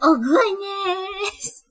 婴儿谈话善良5
描述：这就是我实际上和老鼠以及其他动物说话的方式
标签： 通话 声音 女孩 婴儿 声乐 可爱
声道立体声